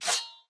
Media:BarbarianKing_base_atk_4.wav 攻击音效 atk 初级和经典及以上形态攻击音效
BarbarianKing_baby_atk_4.wav